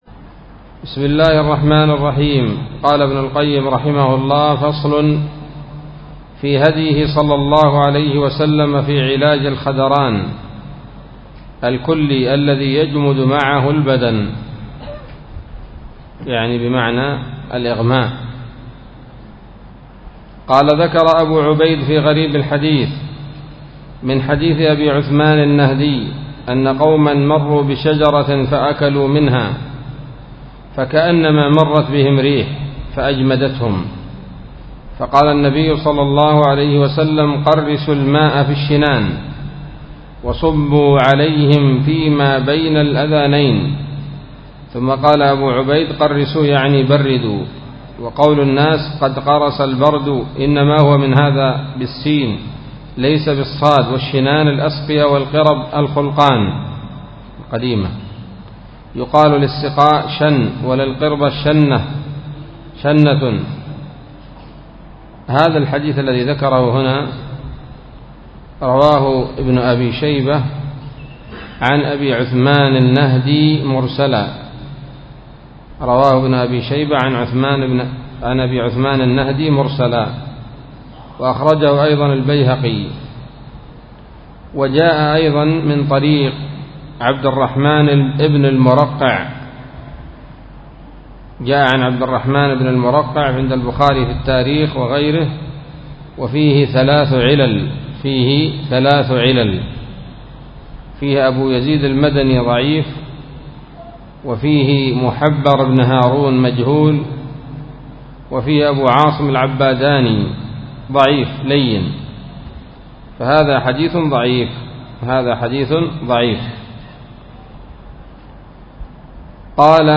الدرس الثلاثون من كتاب الطب النبوي لابن القيم